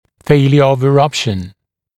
[‘feɪljə əv ɪ’rʌpʃ(ə)n][‘фэйлйэ ов и’рапш(э)н]нарушение прорезывания; непрорезывание (зубов)